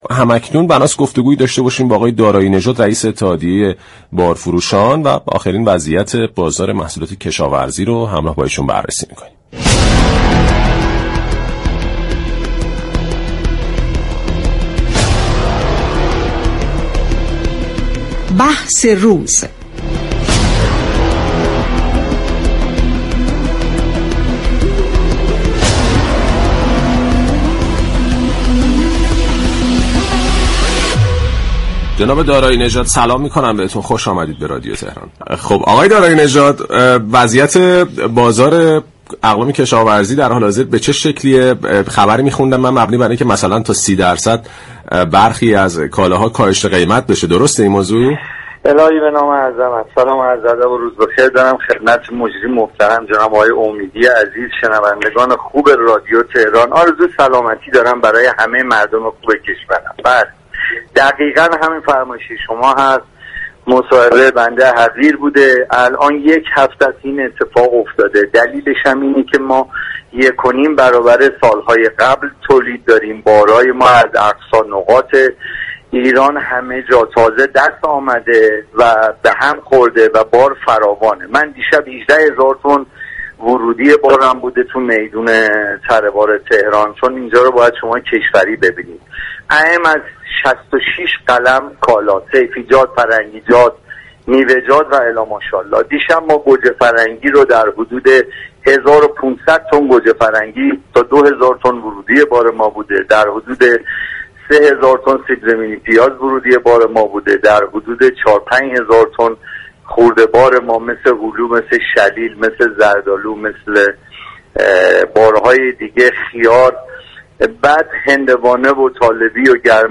گفت‌وگو با رادیو تهران